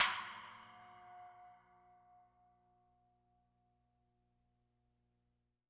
R_B Splash B 02 - Close.wav